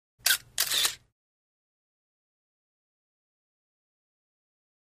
35 mm Still Camera 3; Electronic Shutter Click With Automatic Rewind, Single Picture.